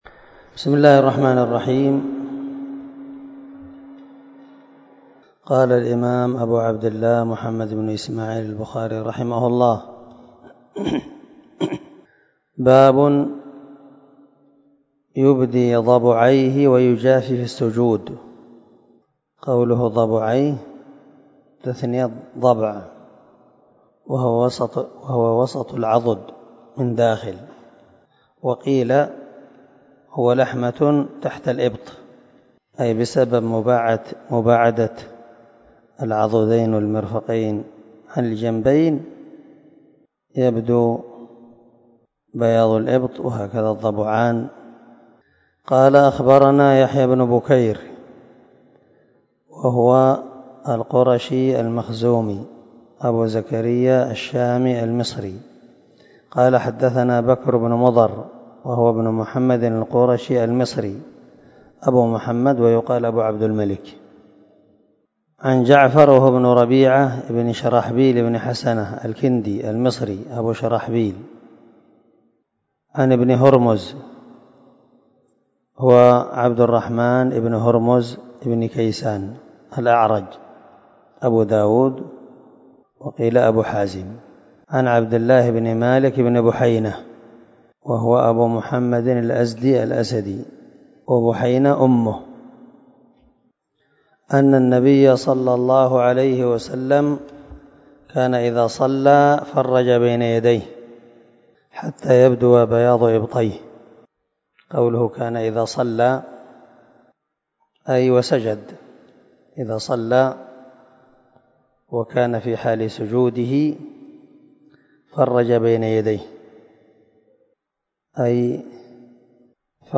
299الدرس 32 من شرح كتاب الصلاة حديث رقم ( 390 - 391 ) من صحيح البخاري